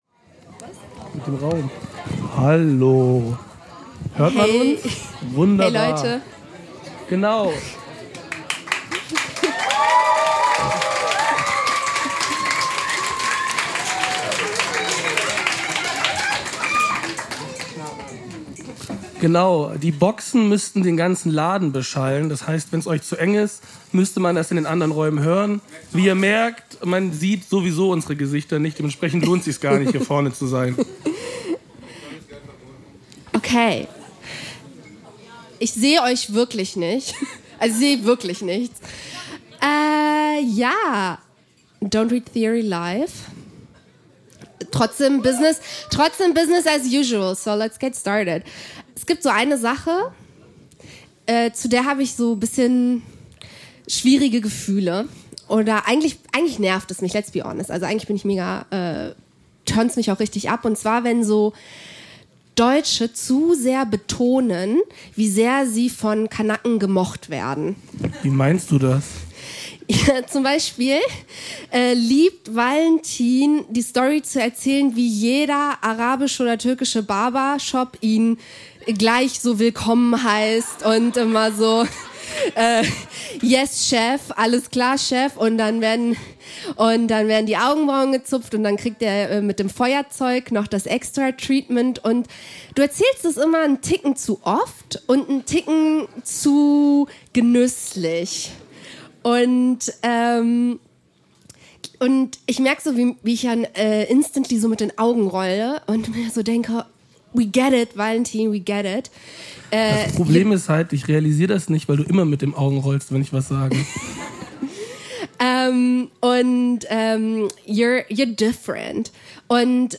DRT macht eine Liveshow an Valentins Namenstag, der nicht zufällig auch der Tag der Liebenden ist. Ganz nach DRT-Manier werden wir uns und euch nichts ersparen: The love and the hate, the pleasure and the pain.